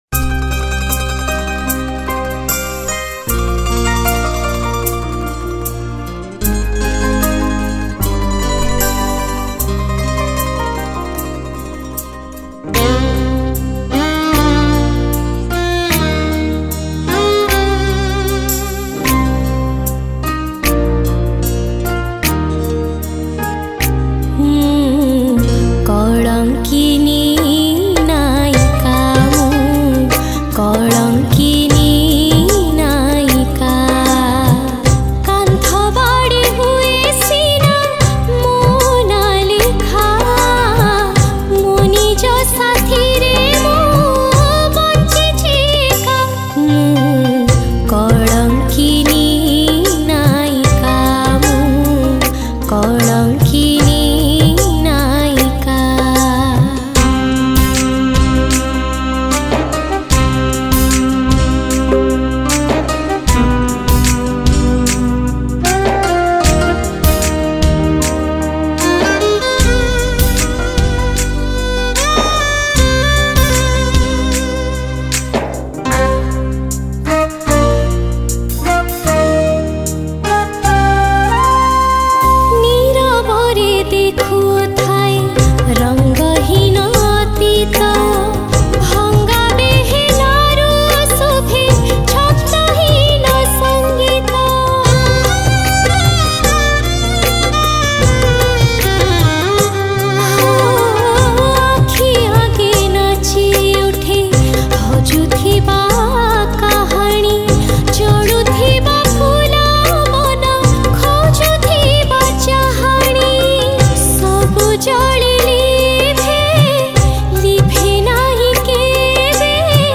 Sad Odia Song